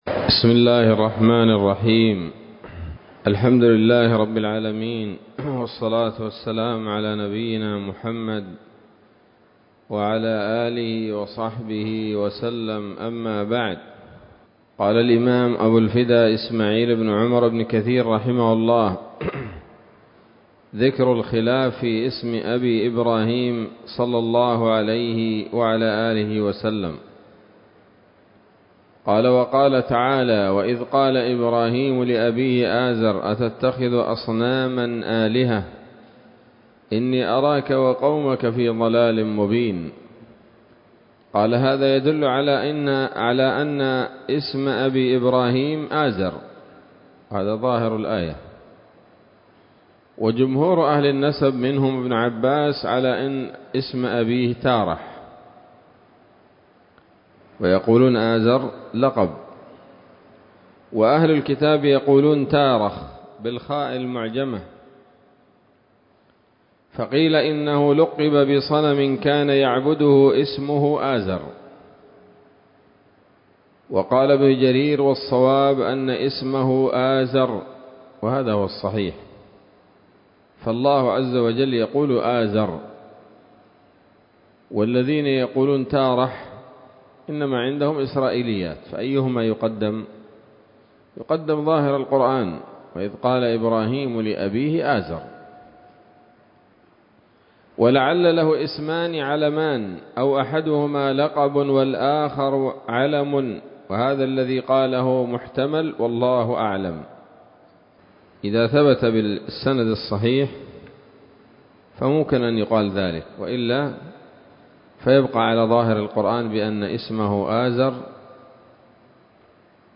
الدرس الحادي والأربعون من قصص الأنبياء لابن كثير رحمه الله تعالى